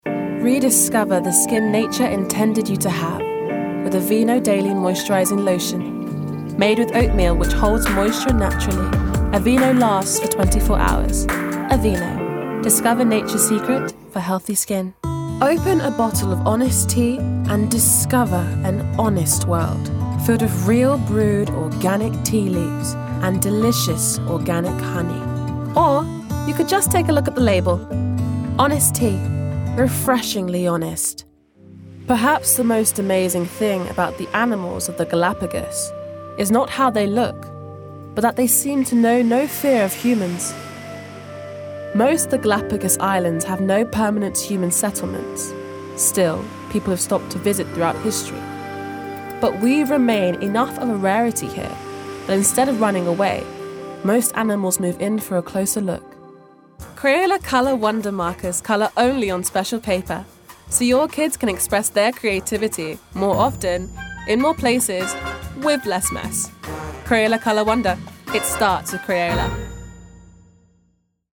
Native voice:
West Midlands
Voicereel: